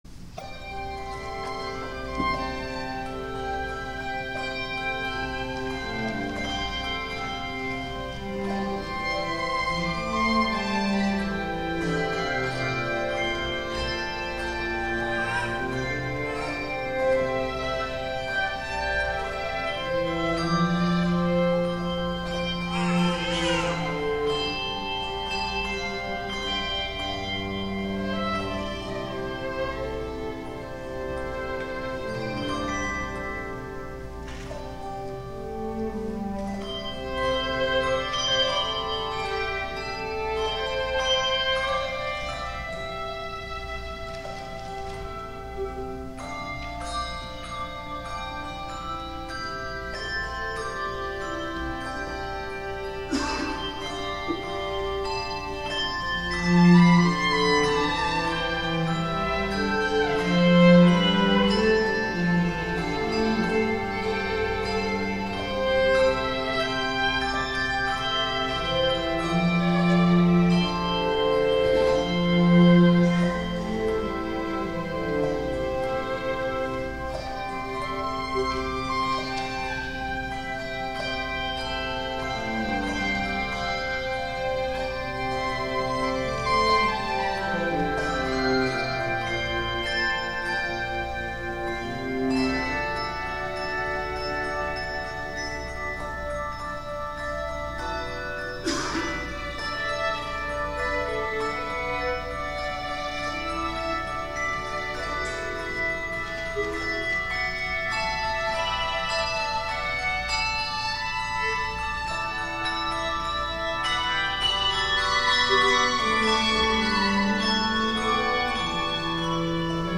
Handbell Choir
cello
violin